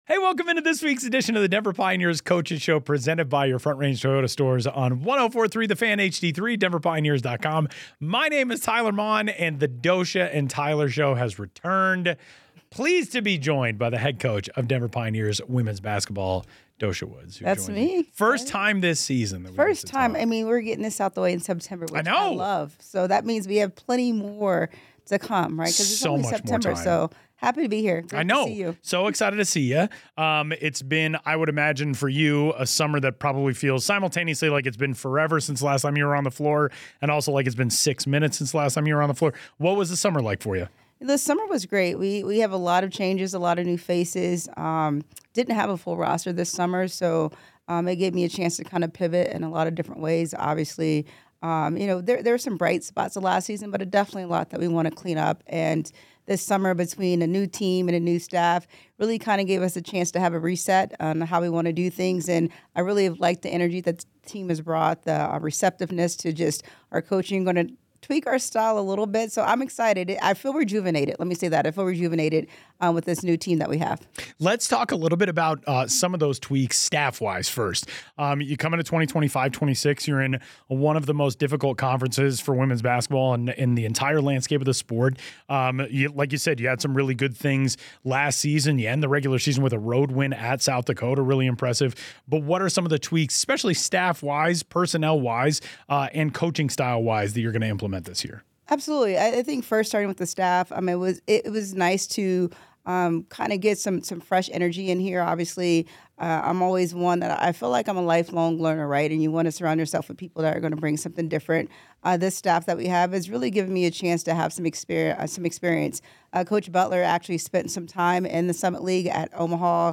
The Denver Pioneers Coaches' Show is from Your Front Range Toyota Stores Studios.